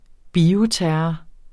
Udtale [ ˈbiːo- ]